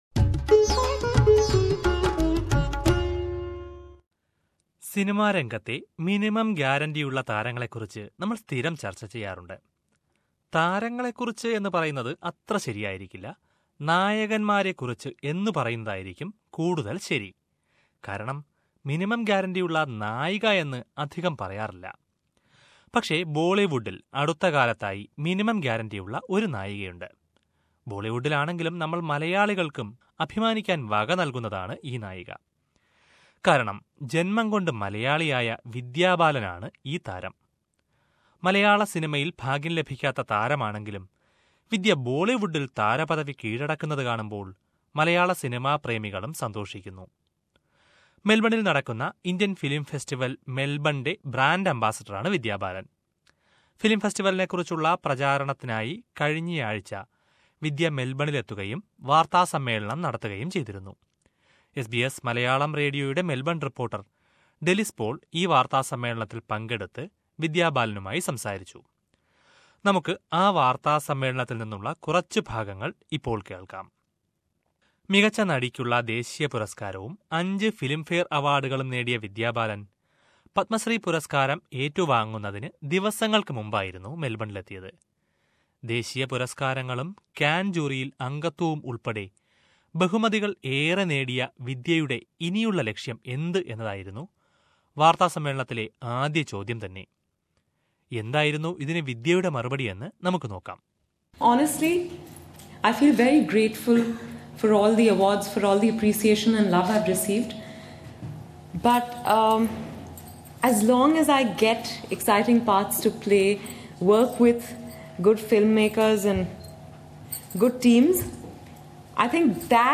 Vidya Balan is the brand ambassador of Indian Film Festival Melbourne. During the Festival launch press conference, she talked about her personal life and film career.